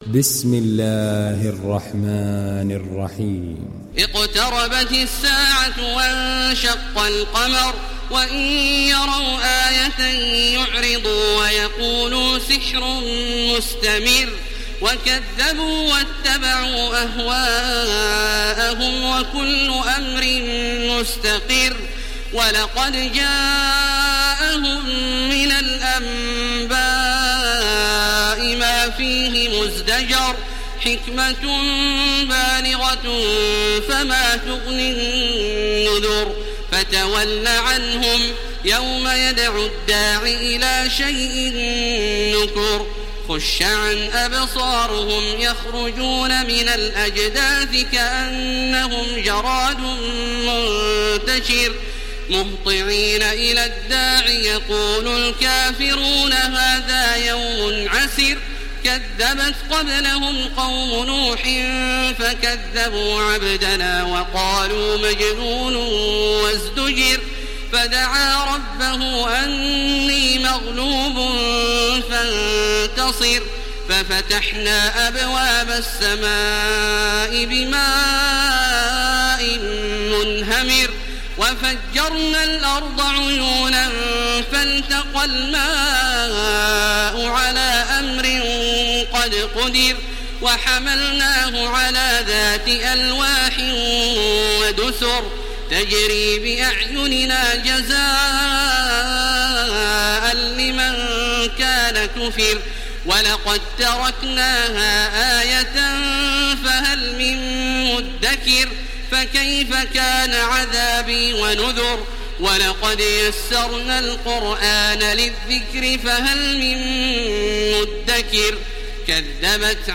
Surat Al Qamar Download mp3 Taraweeh Makkah 1430 Riwayat Hafs dari Asim, Download Quran dan mendengarkan mp3 tautan langsung penuh
Download Surat Al Qamar Taraweeh Makkah 1430